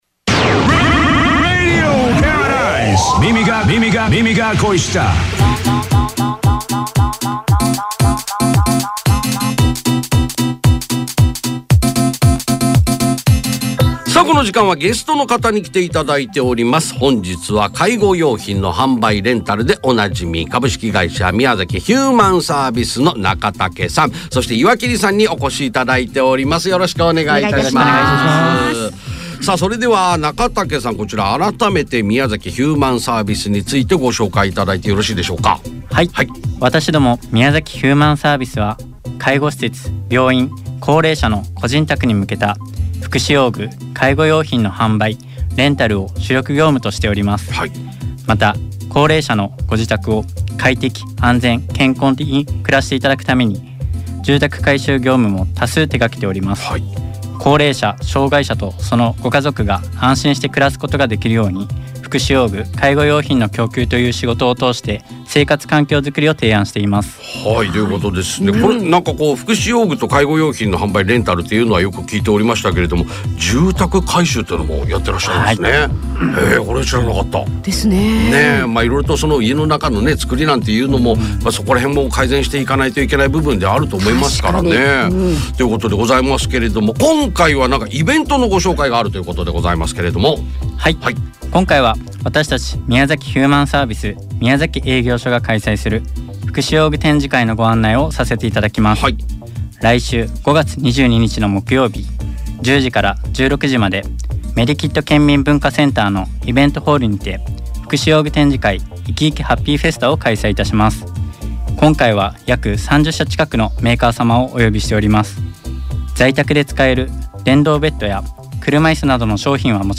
FMラジオ『耳が恋した』にPR出演しました♫ - 宮崎ヒューマンサービス
ずっと前から緊張していました！